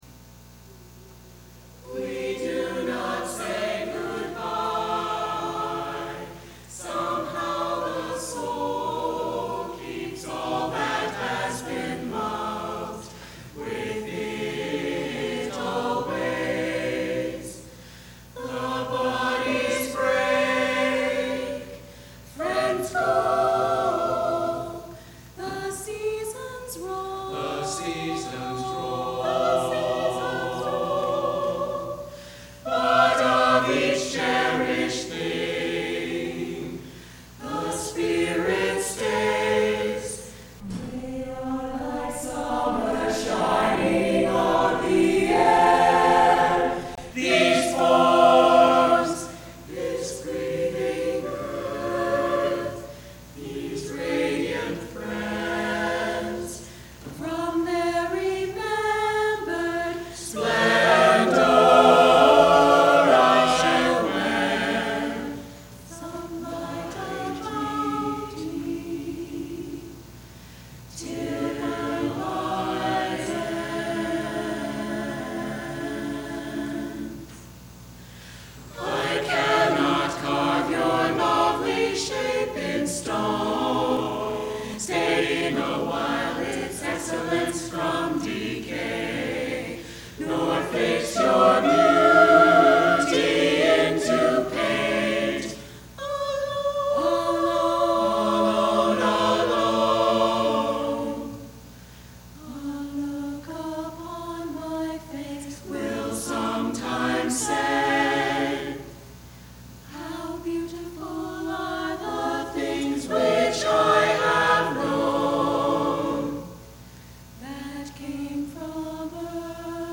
We Do Not Say Good-bye (SATB a cappella)
recording of the JUUL Tones a cappella group singing the piece in the annual Service of Music at the First Unitarian Universalist Church of San Diego on 19 May 2013. (This recording was edited to incorporate a change in measure 21 made after the performance, and to fix a spot in the recording where there was a small flub with the lyrics.)